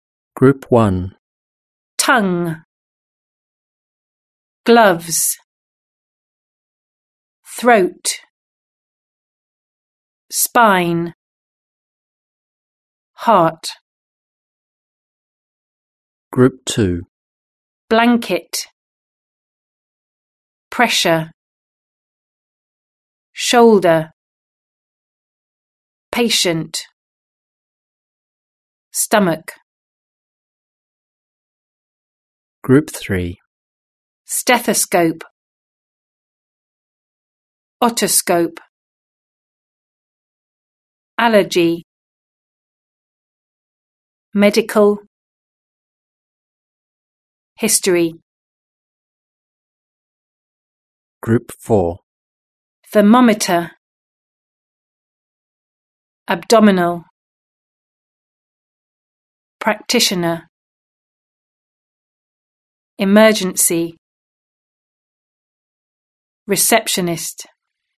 12. Pronunciation: Word stress.